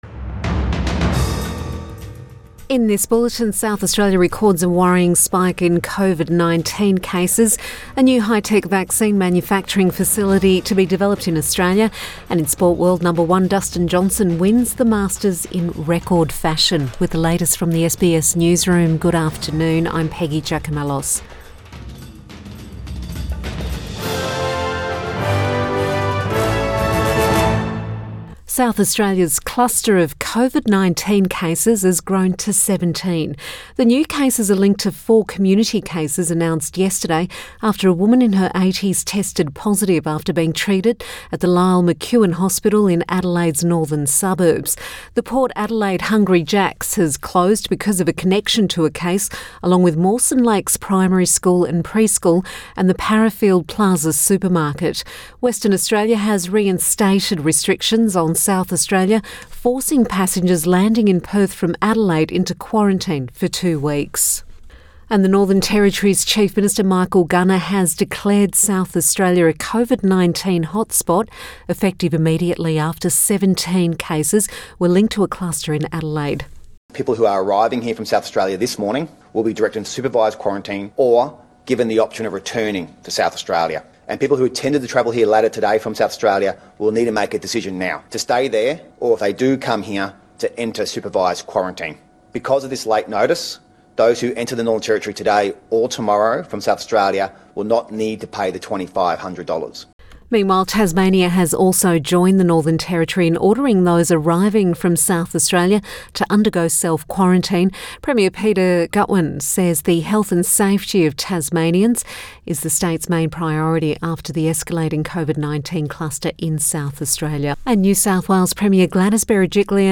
Midday bulletin 16 November 2020